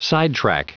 Prononciation du mot sidetrack en anglais (fichier audio)
sidetrack.wav